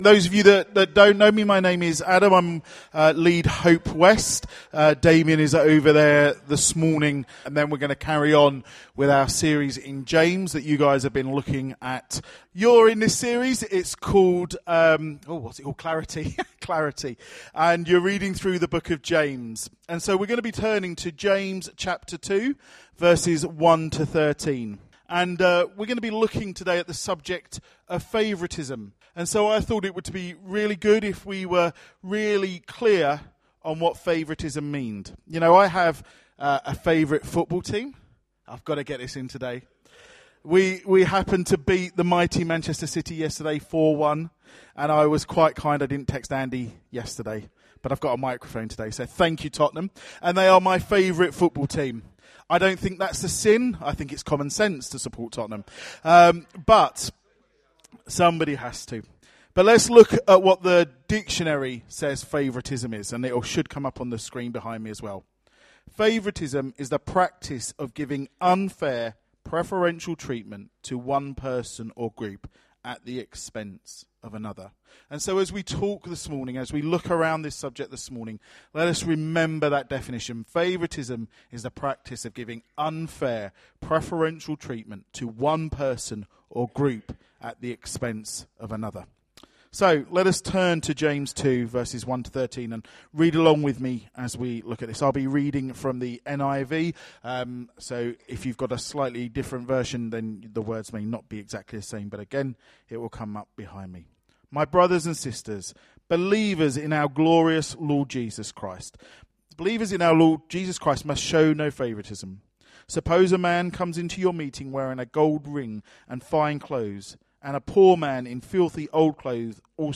Notes Sermons in this Series Continuing the series on Clarity